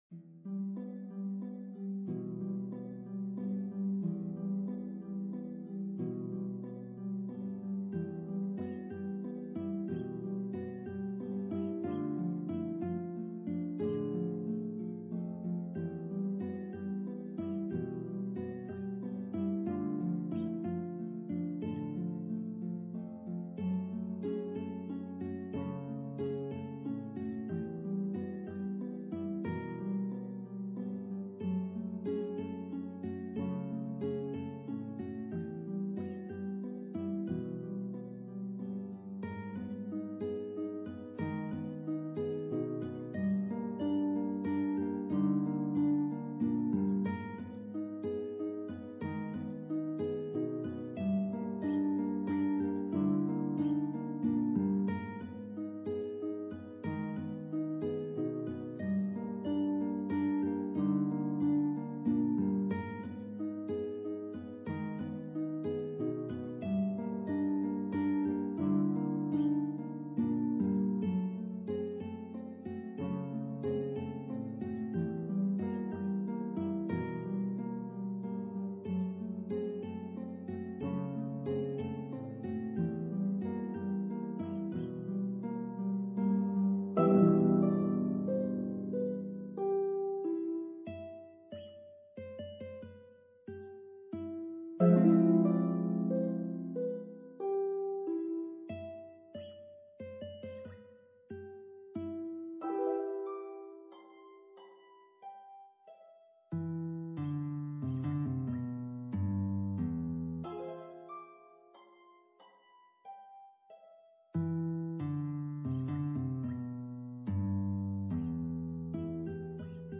for guitar and harp